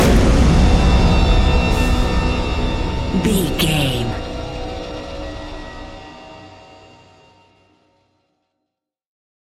Fast paced
In-crescendo
Ionian/Major
E♭
industrial
dark ambient
EBM
drone
synths
Krautrock
instrumentals